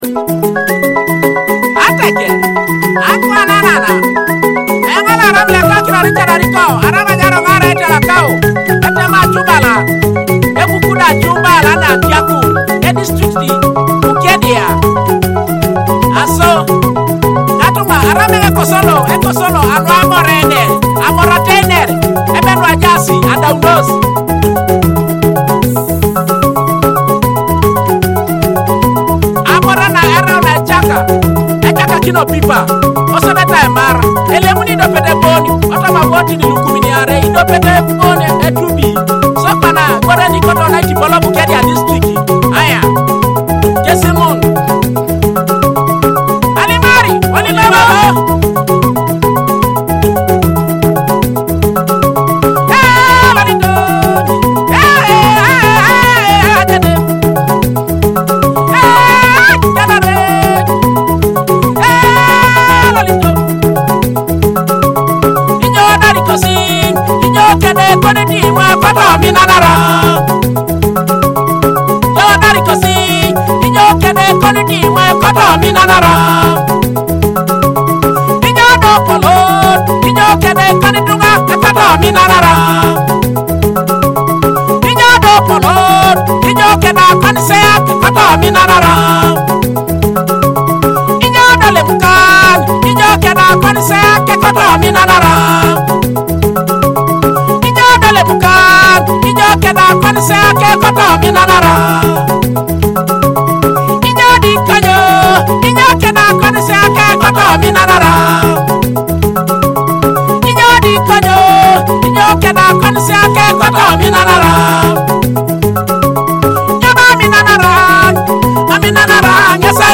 Teso cultural and traditional rhythms in Akogo and Adungu
Akogo (thumb piano)
Adungu (arched harp)